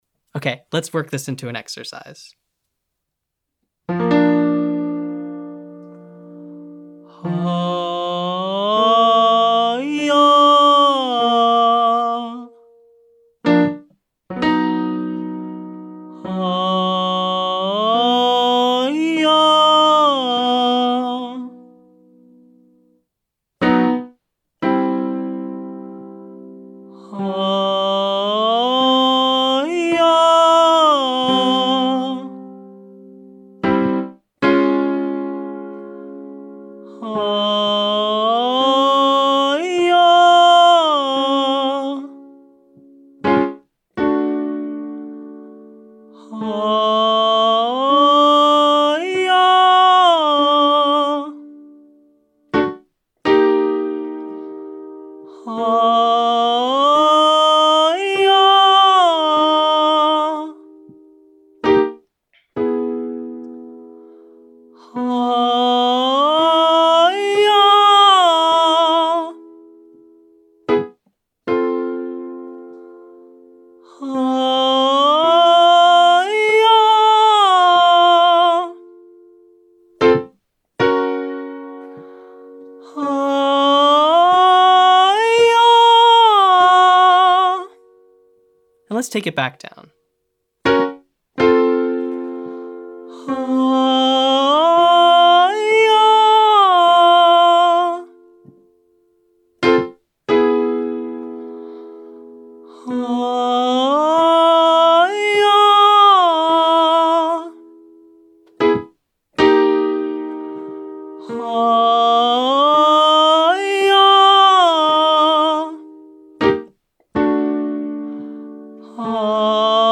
Exercise 3: Huh - Yah Sigh 5-8, 10-8, up then down